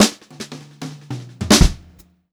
152H2FILL2-R.wav